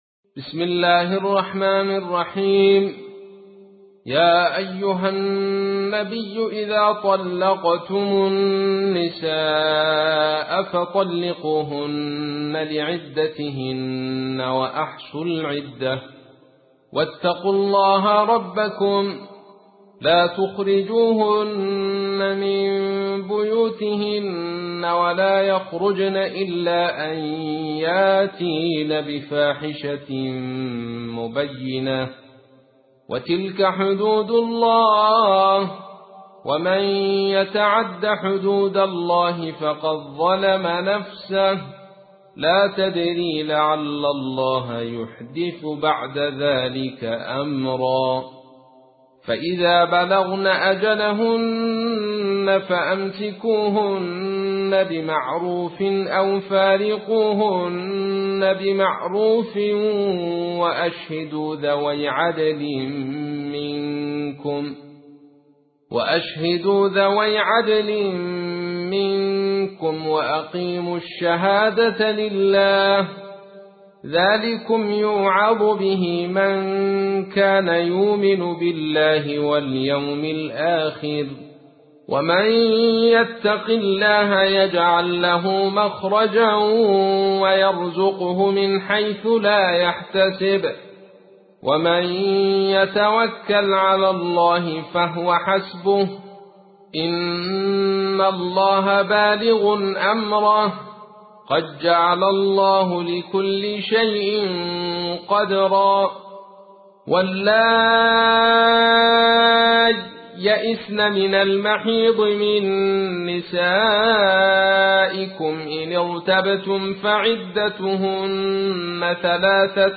65. سورة الطلاق / القارئ